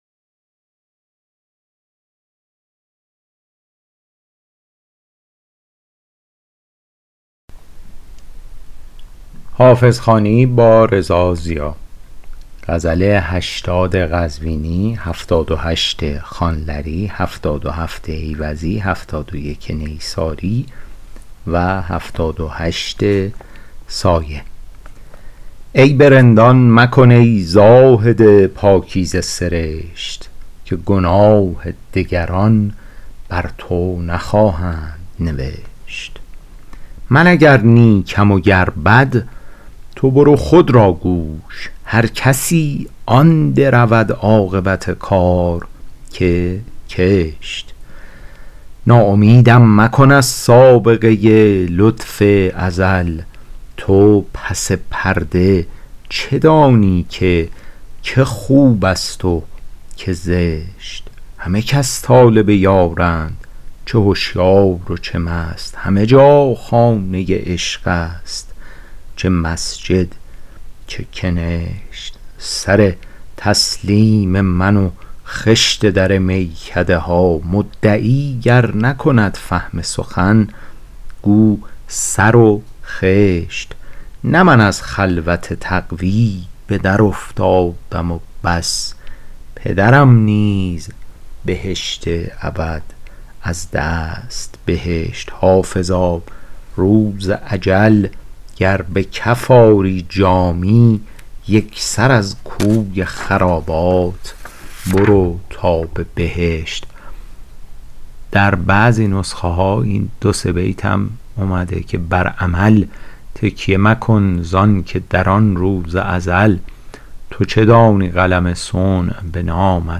شرح صوتی غزل شمارهٔ ۸۰